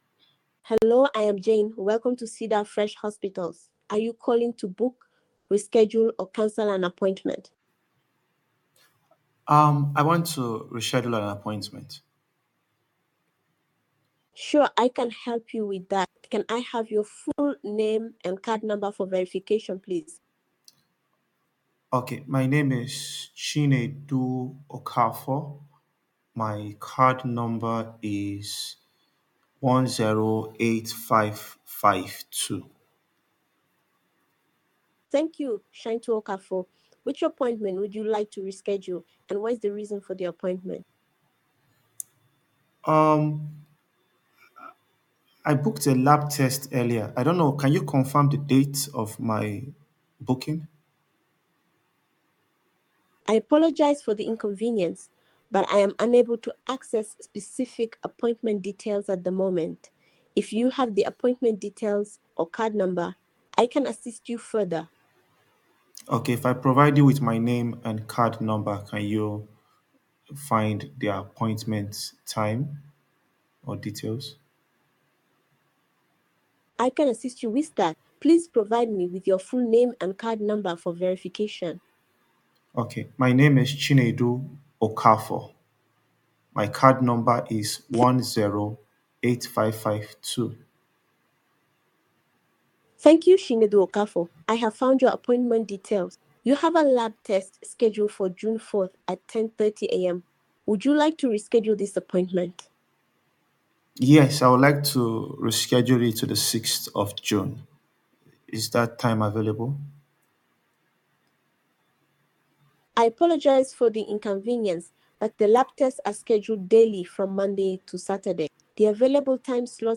subscription-based army of call center voice agents
with 80+ African voices.